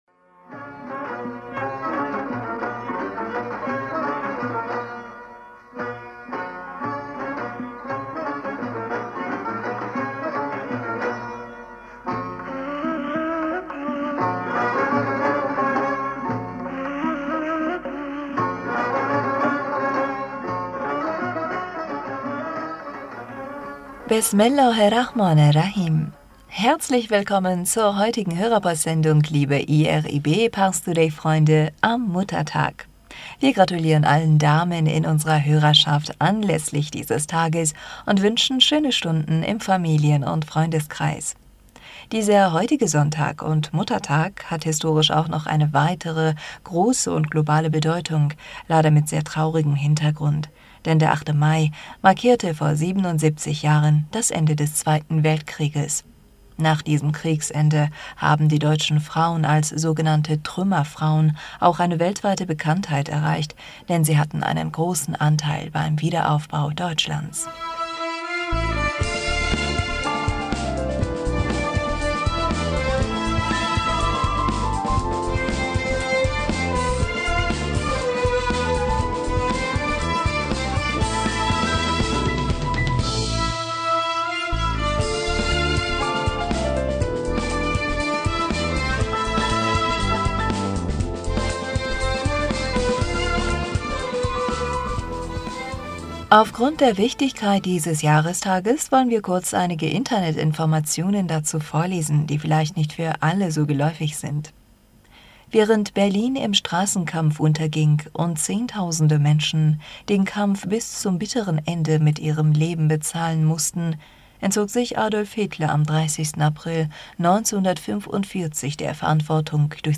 Hörerpostsendung am 8. Mai 2022 Bismillaher rahmaner rahim - Herzlich willkommen zur heutigen Hörerpostsendung liebe IRIB-ParsToday-Freunde am Muttertag!...